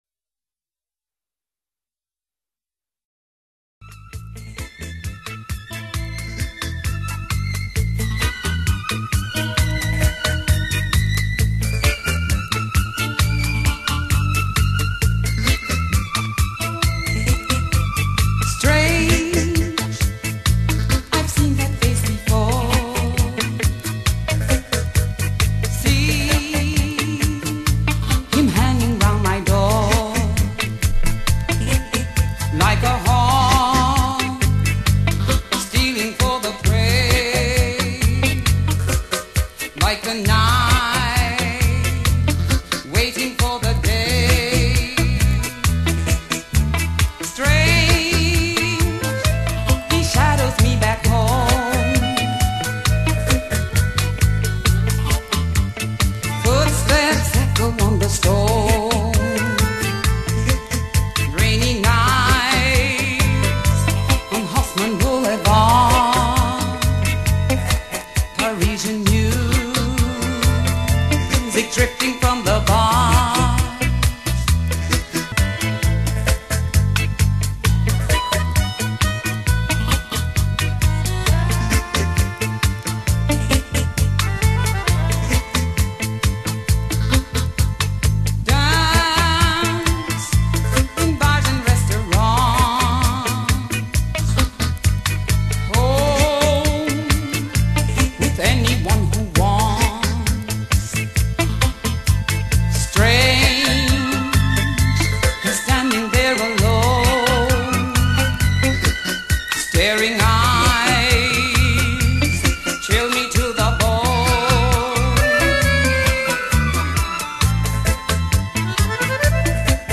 This special tango